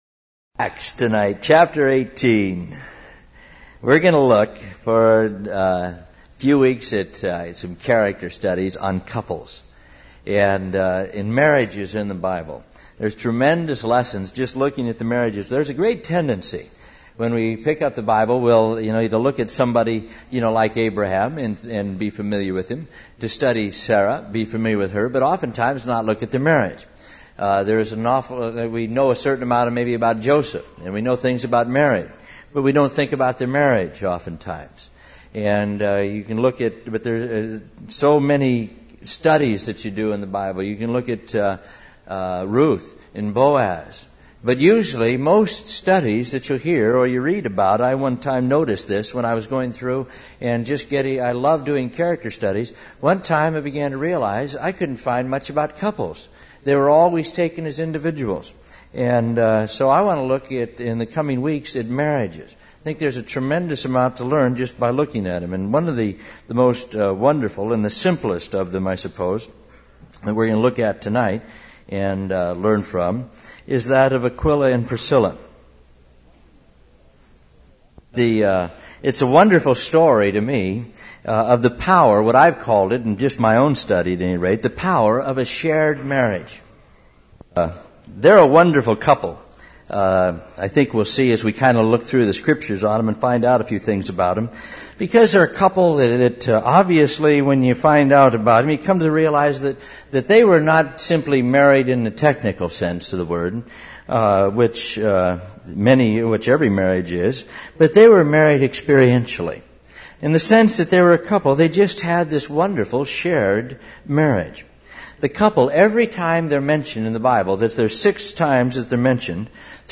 In this sermon, the speaker emphasizes the importance of investing one's life into others and seeing the kingdom of heaven increase.